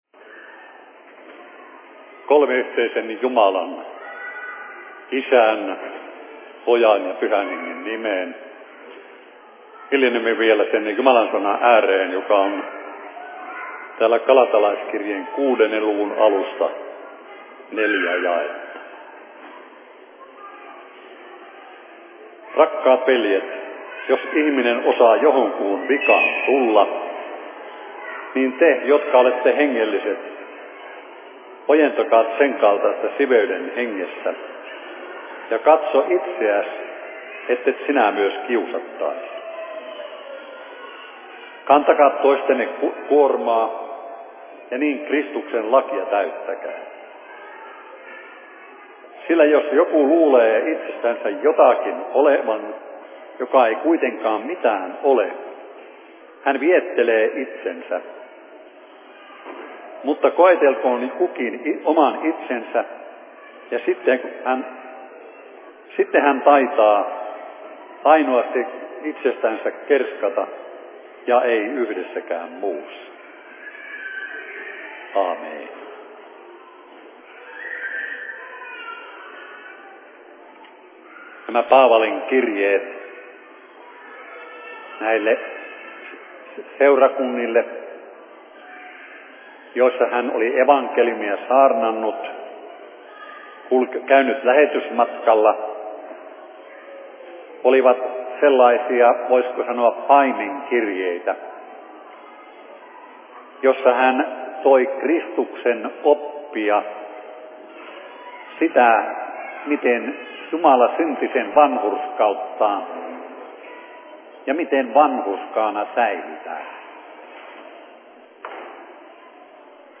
Alajärven maakunnalliset kesäseurat/Seurapuhe 28.07.2013
Paikka: Rauhanyhdistys Alajärvi